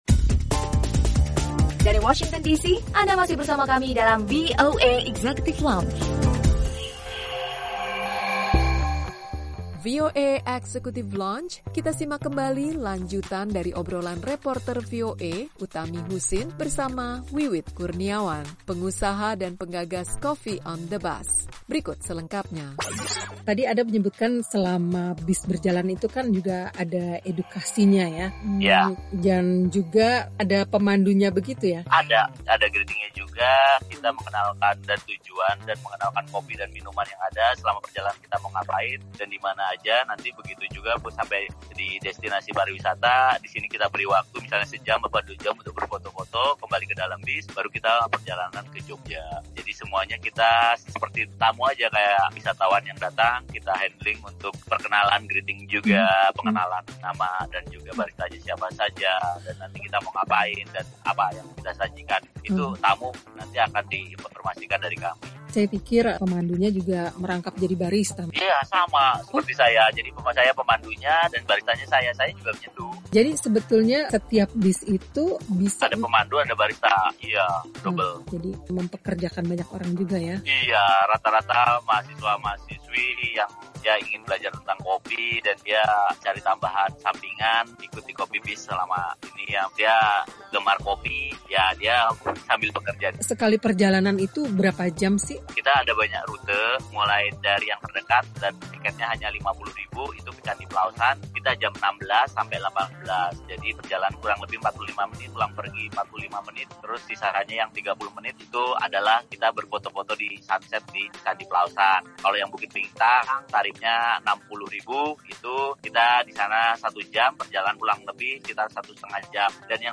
obrolan